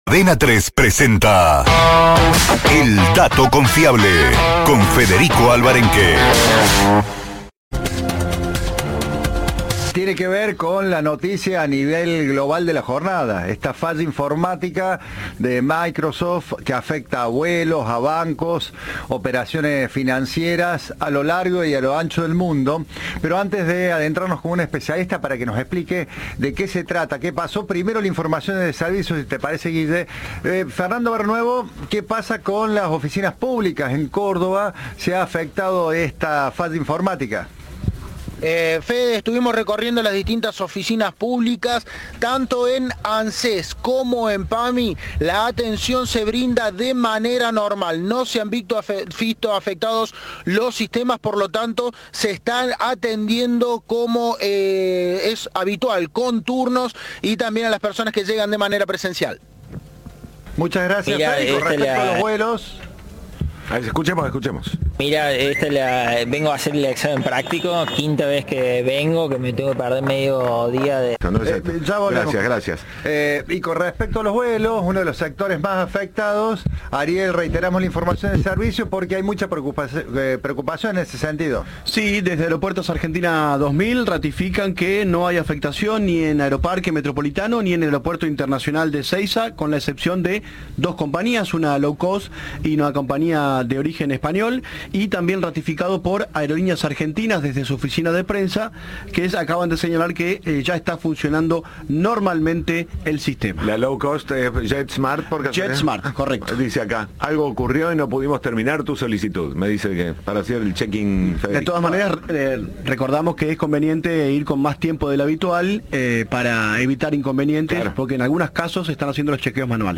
abogado especialista en ciberseguridad explicó en Cadena 3 el alcance de este ataque y cómo impactó en diferentes entidades.